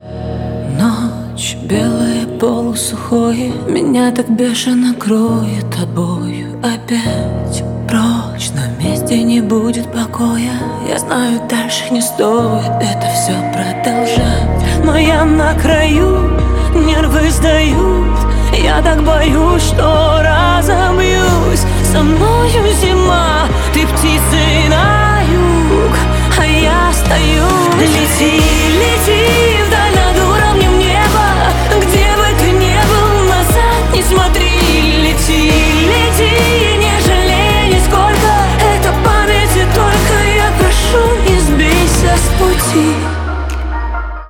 • Качество: 128, Stereo
поп
саундтрек